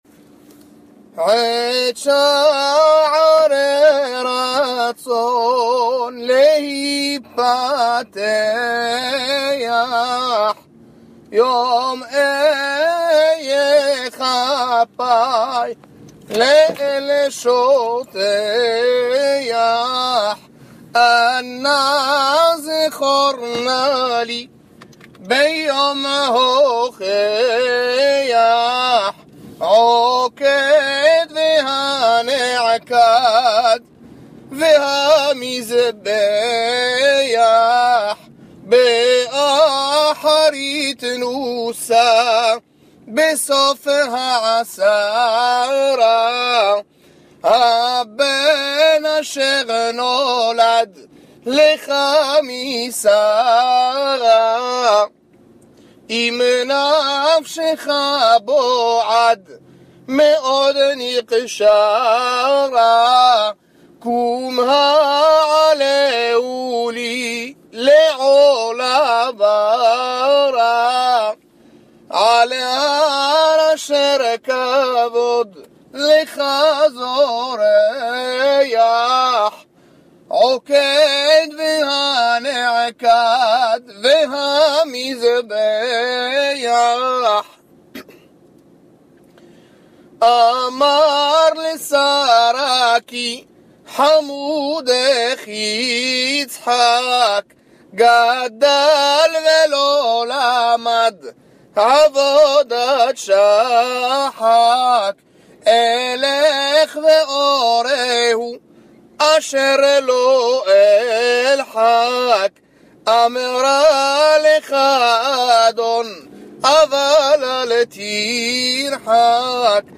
Pour apprentissage Hazanim et Kahal. On invoque la ligature d'Its'hak
Hazanout
Ce Piyout est lu généralement avant de sonner du Chofar (Tékiot déméyoushav). Cet enregistrement est découpé selon 3 airs (premier changement à Sisou Léimi et second à Livritékha Shokhen)